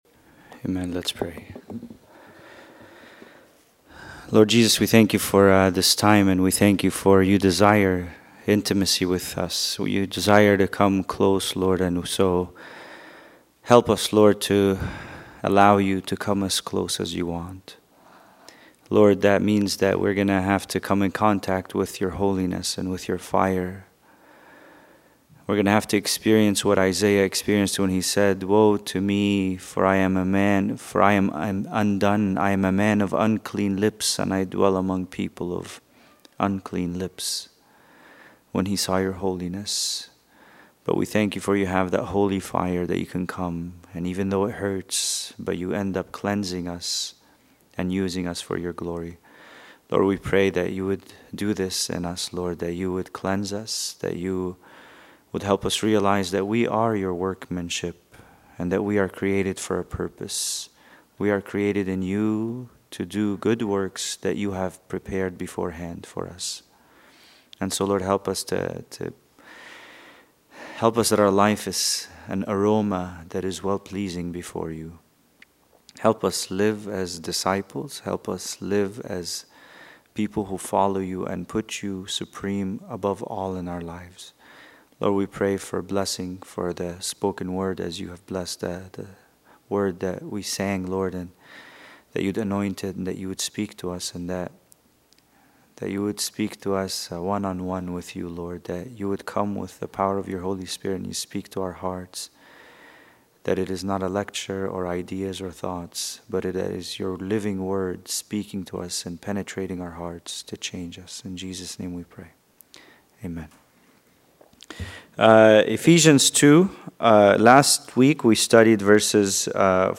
Bible Study: Ephesians 2:16-18